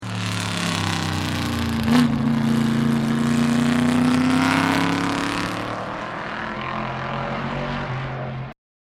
Hockenheim Historic 2011 - NK HTGT - Tourenwagen und GT bis 1965
Hockenheim Historic 2011 - Chevrolet Corvette Grand Sport 1963
Chevrolet__Corvette_Grand_Sport__1963.mp3